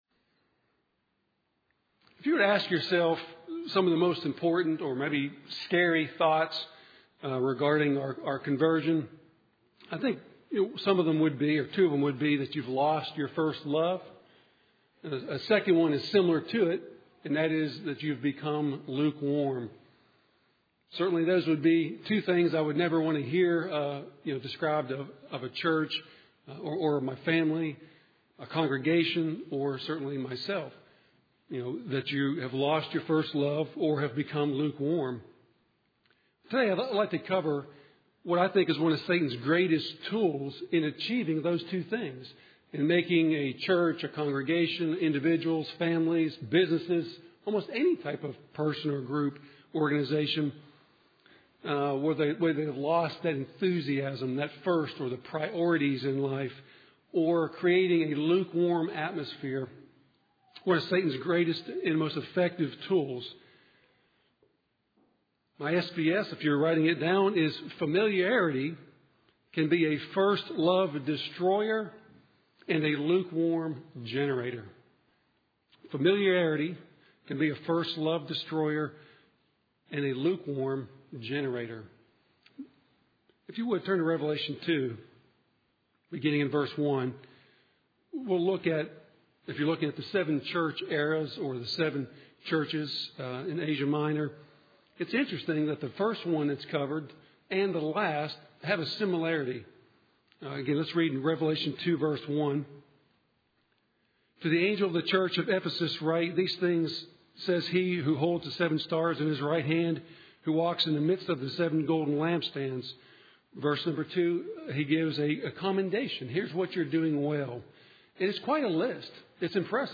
Sermons
Given in Nashville, TN Bowling Green, KY